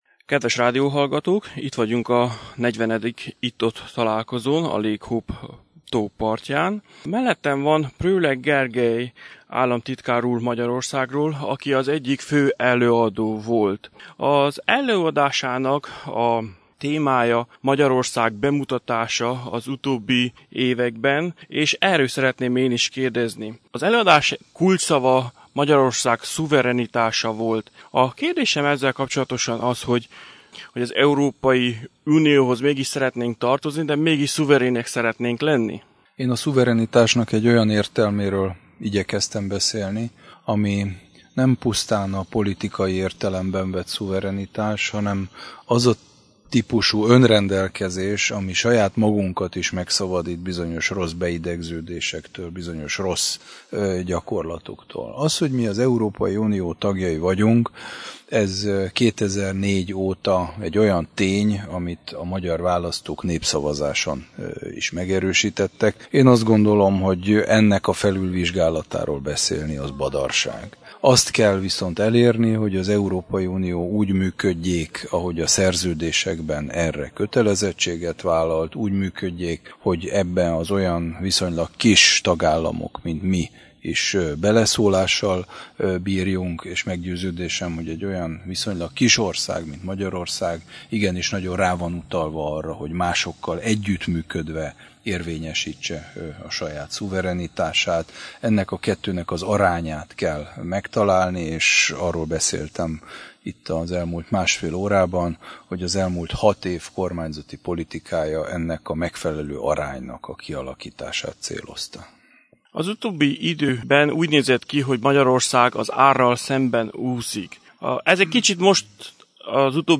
Természetesen a Bocskai Rádiónak is szívesen adott egy rövid interjút, amelyben sok témát érintettek, többek között a nemzeti szuverenitás kérdését is, amelynek fontosságát és annak megőrzését első számú feladatként említi a mostani magyar kormány számára is. A helyettes államtitkár úr az 1956-os forradalom és szabadságharc 60. évfordulójának legfontosabb üzenetét úgy fogalmazta meg, hogy meg kell becsülni azokat a hősöket, akik annak idején ezt a harcot vállalták.